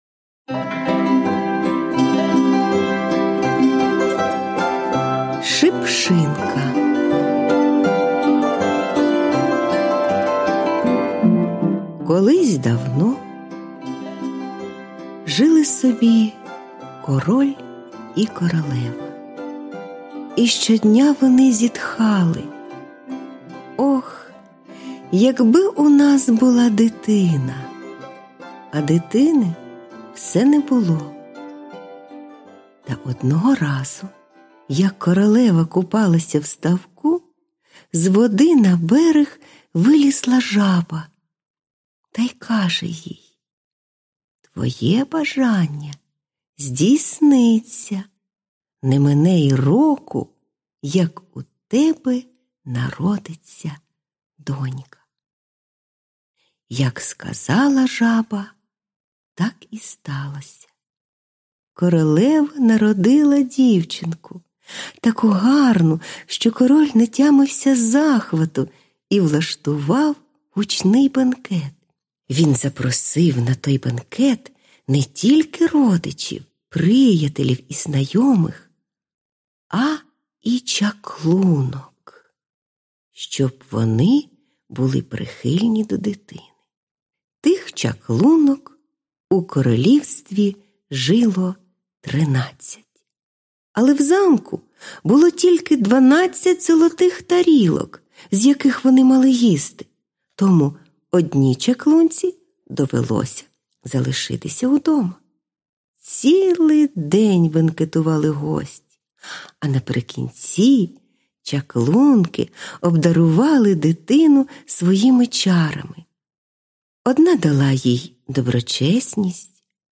Аудіоказка Шипшинка